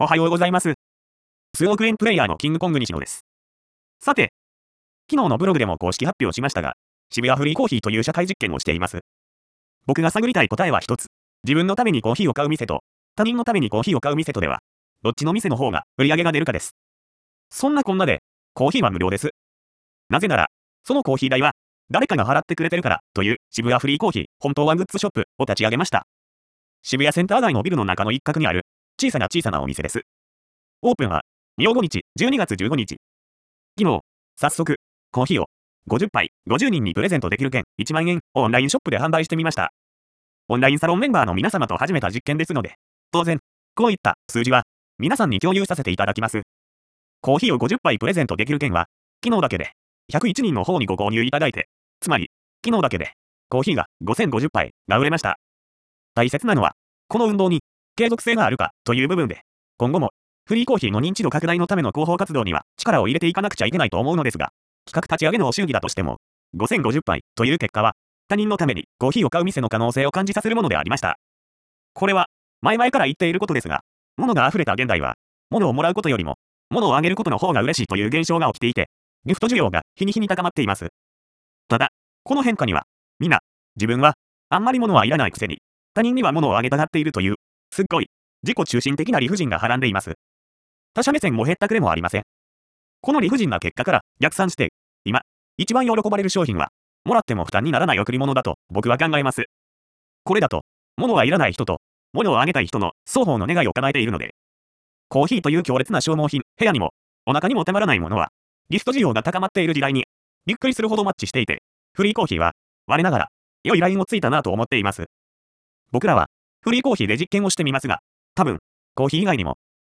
（機械音なので聞き取りづらいところもあります。漢字の読みまちがいやご意見あればコメントお願いします）